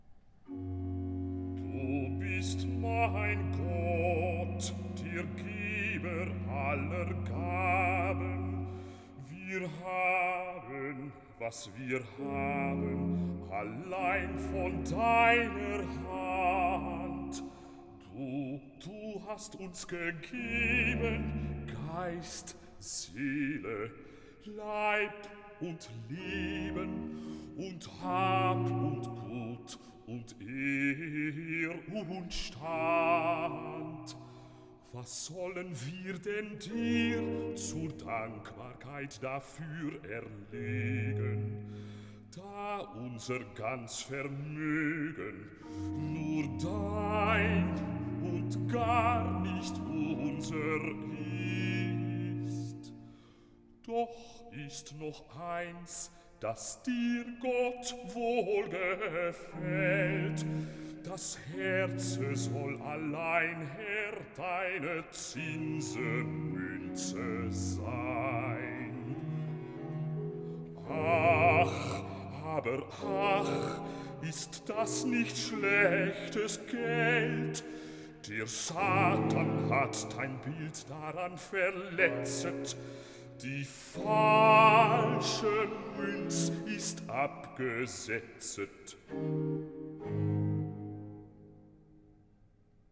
Recitativo - Basso